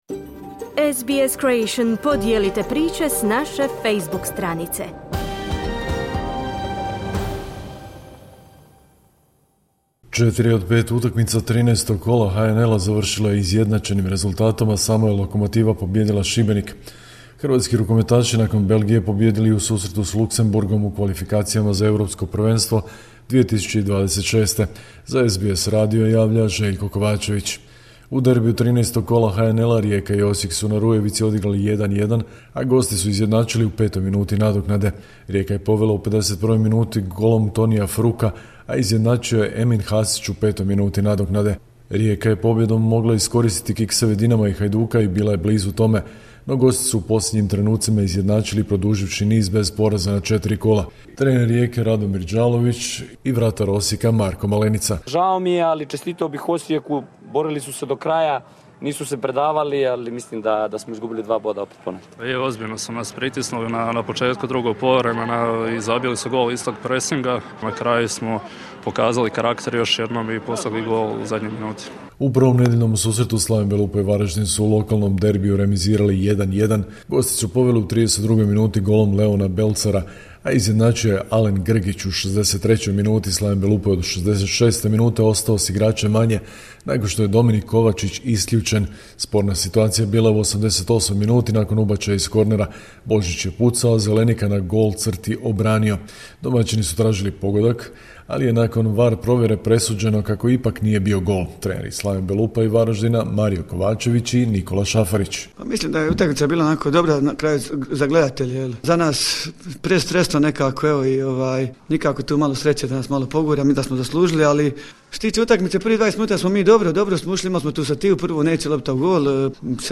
Sportske vijesti iz Hrvatske, 11.11.2024.